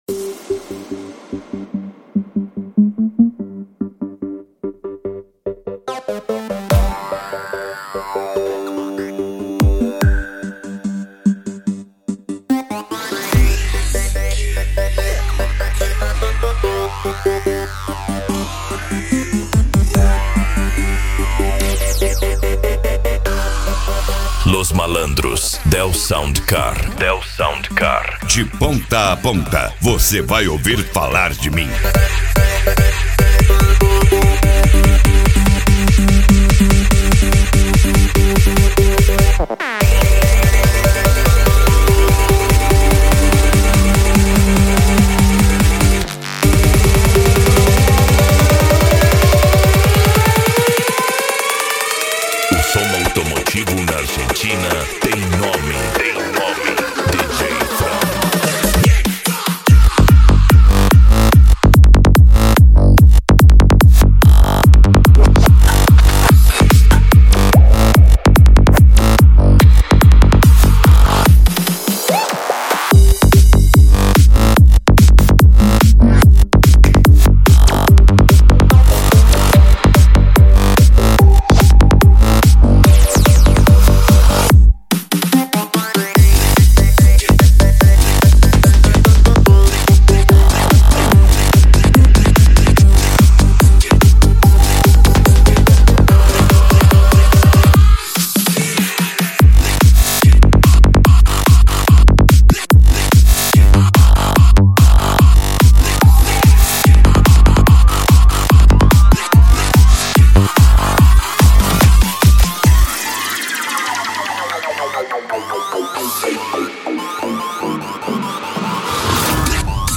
Bass
Eletronica
Psy Trance